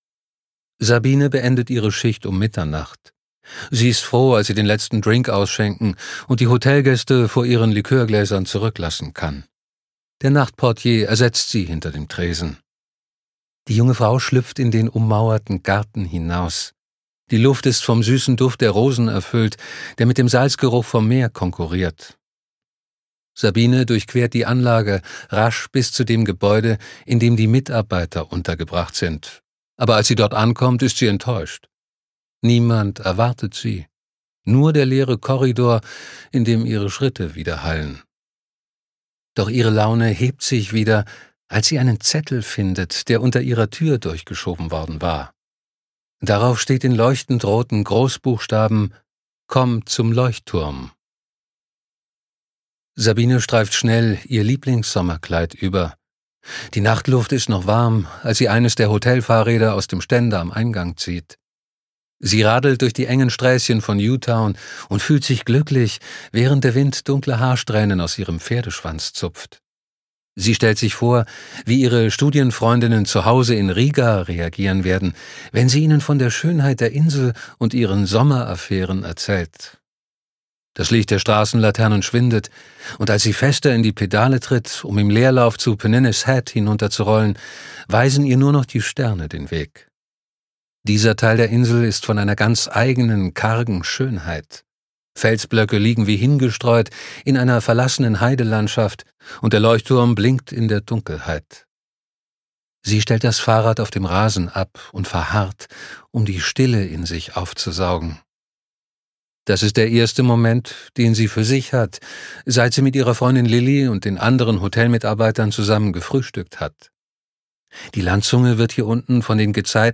Ein Krimi auf den Scilly-Inseln
Gekürzt Autorisierte, d.h. von Autor:innen und / oder Verlagen freigegebene, bearbeitete Fassung.